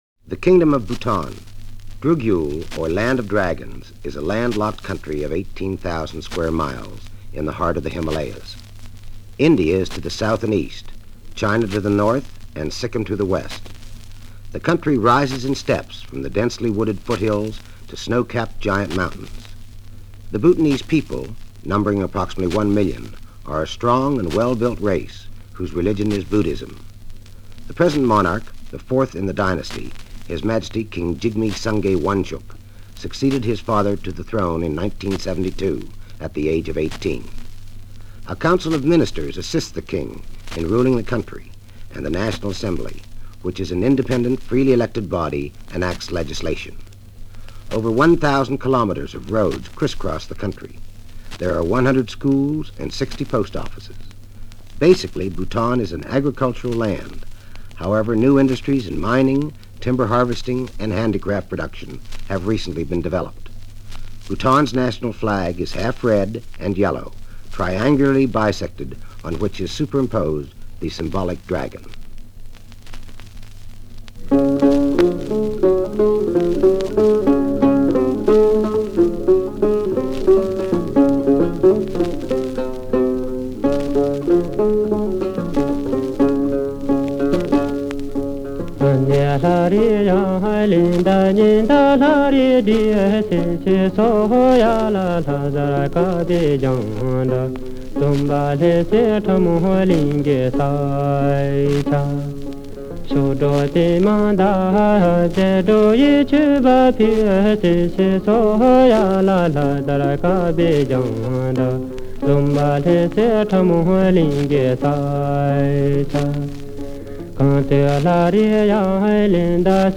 9 NU – a) Bhutan History (English) b) Folk Song (No. 1) c) Folk Song (No. 2)
These tiny records – possibly the smallest vinyl records that can still be played with a stylus – have an adhesive backside to affix to either a letter to postcard.